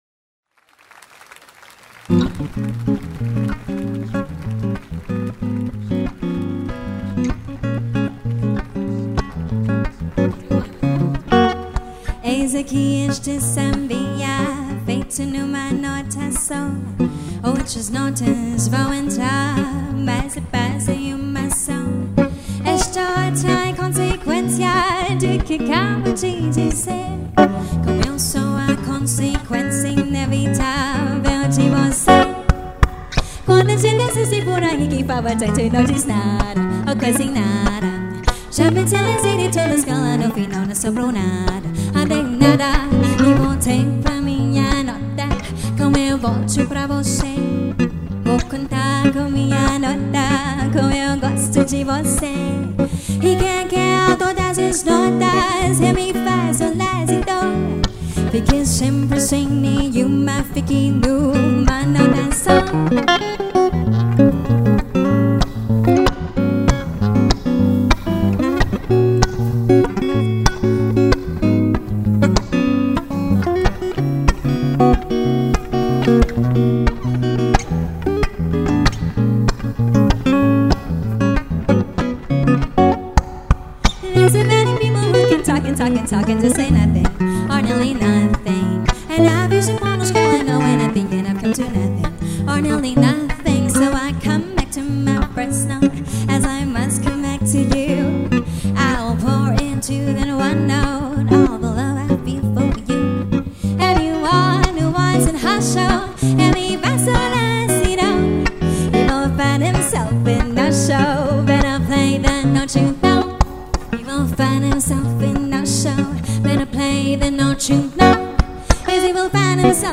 柔美的爵士女声总是让人喜爱
推荐2首桑巴风格的歌曲, 让大家共享一下啊 1.